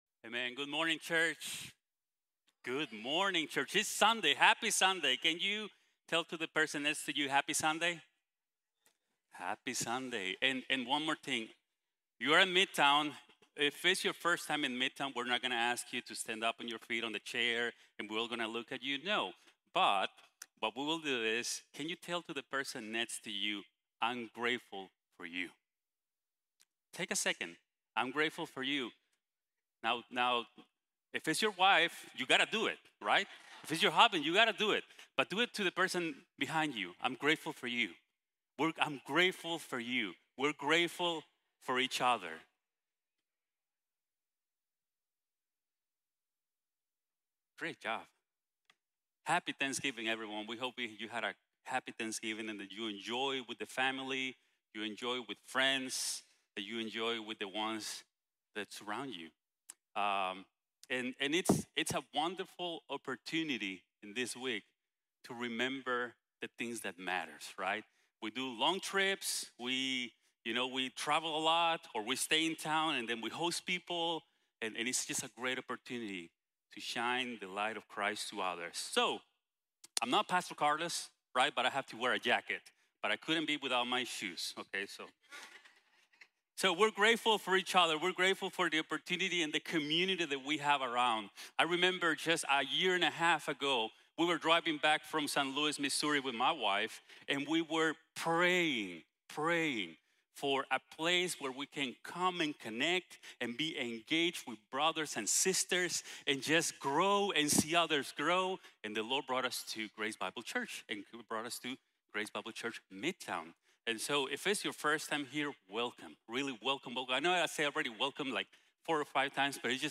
Haciendo de Historias | Sermon | Grace Bible Church